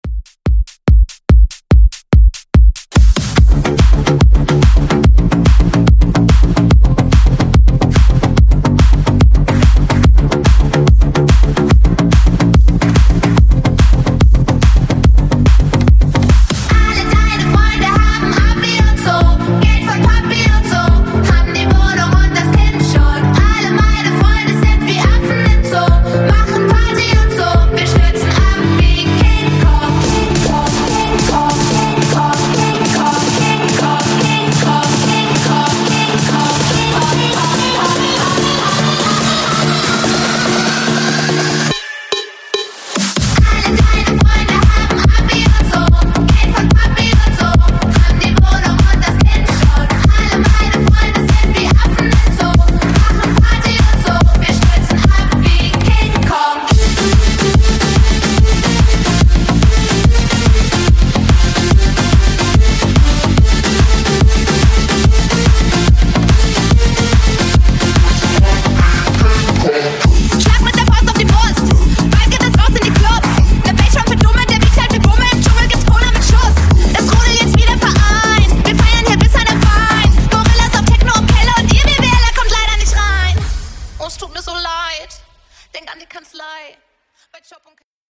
Genre: LATIN
Dirty BPM: 94 Time